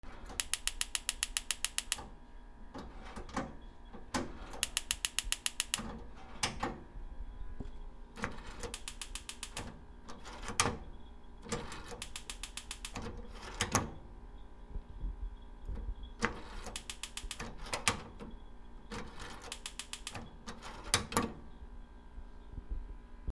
ガスコンロ 点火